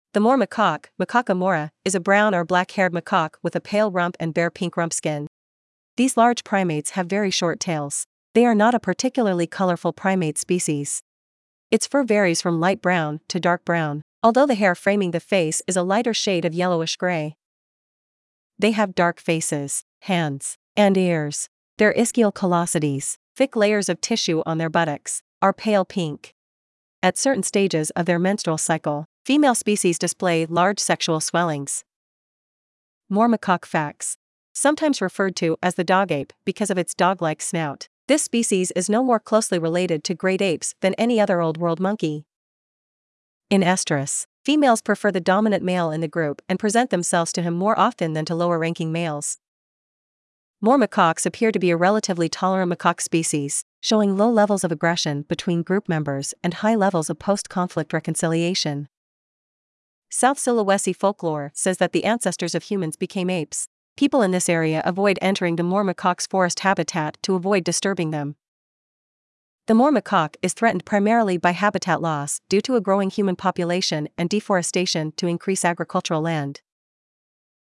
Moor Macaque
Moor-Macaque.mp3